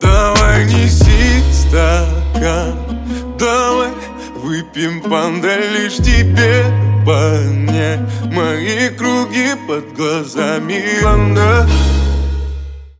Жанр: Хип-хоп / Русский рэп